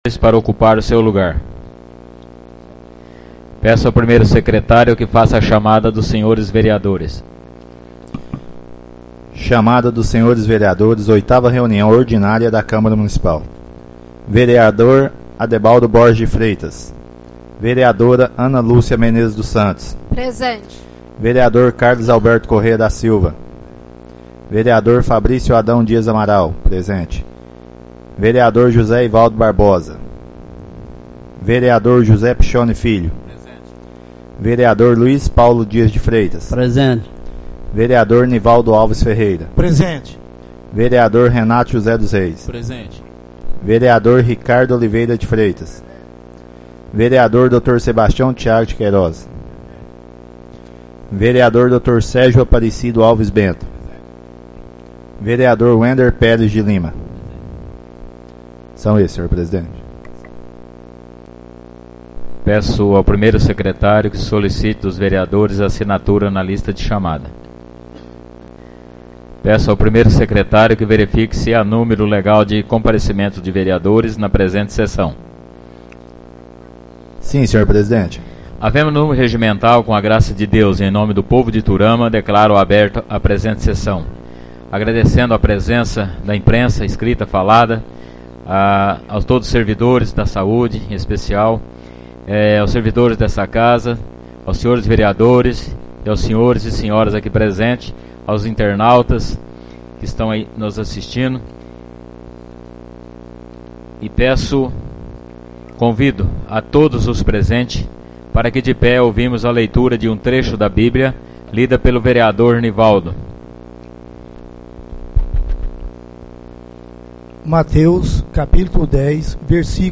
Audio da 8ª Reunião Ordinária de 2017